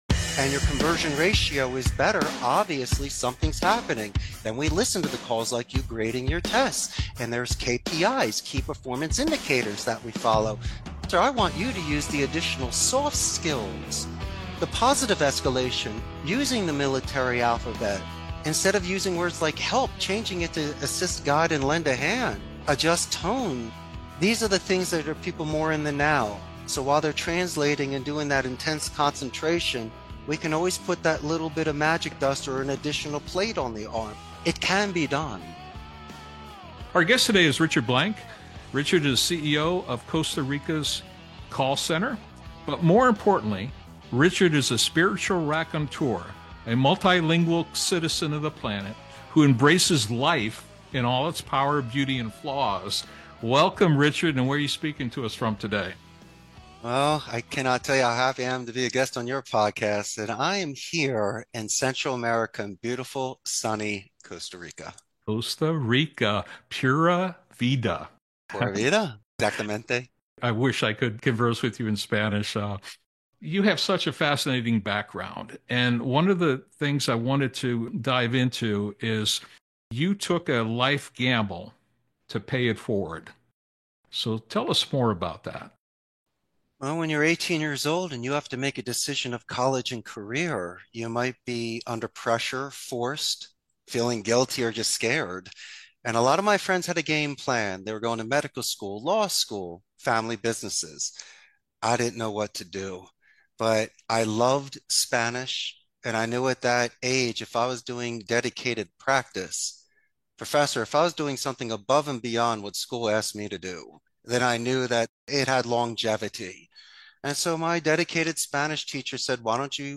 Manager Memo podcast A biweekly podcast focused on the planning, organizing, leading, and evaluating functions of management. Interviews with leading business practitioners provide tantalizing tips, wizardly wisdom and energized encouragement to our listeners.